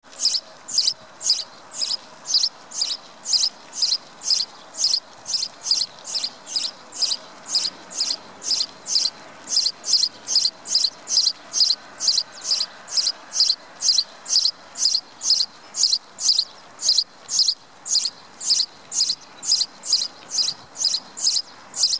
Звуки воробья
Городской воробей звонко чирикает